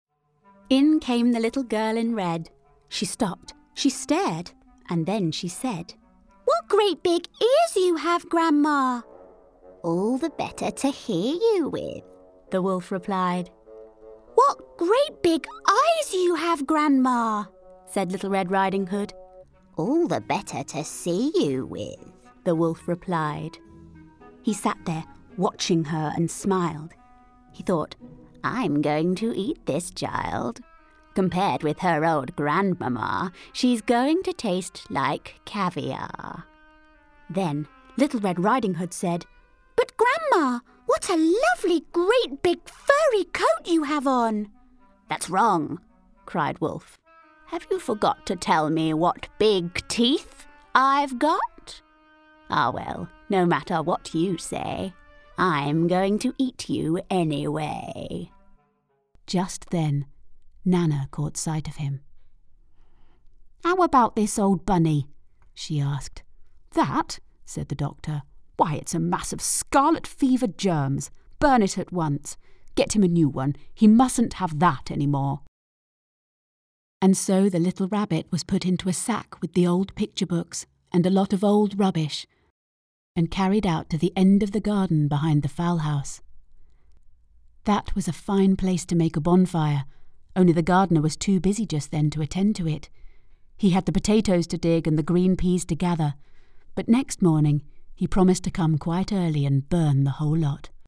Engels (Brits)
Natuurlijk, Veelzijdig, Vriendelijk, Warm, Zakelijk